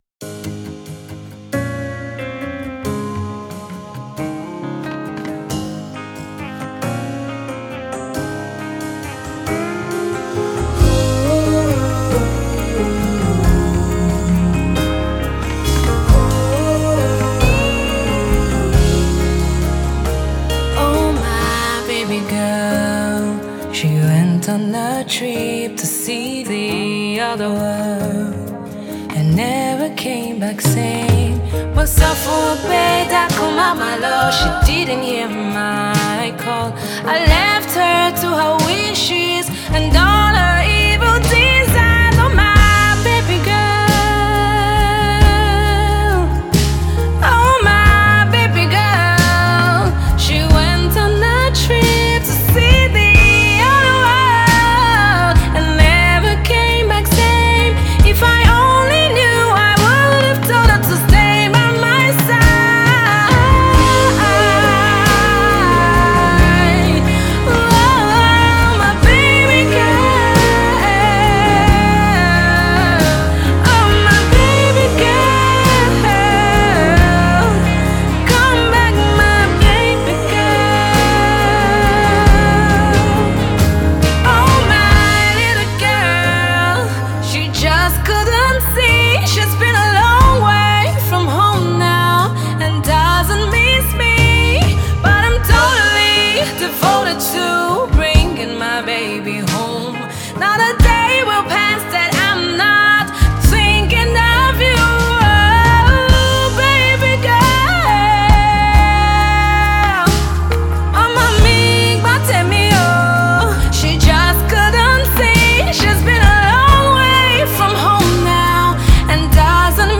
soul/RnB